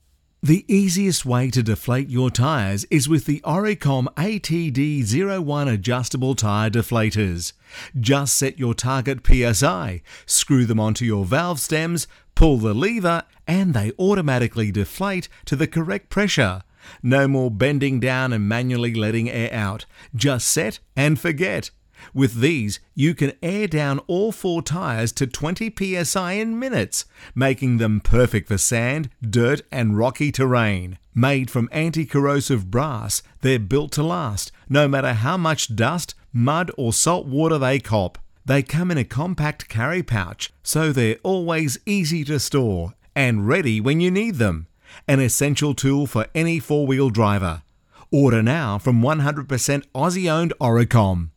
Professionally recorded phone messages
Male and female voice artists have clear enunciation and exceptional voice skills to convey your message in a friendly, pleasant manner.
Male voice - Style 3
Male-voice-style-3.mp3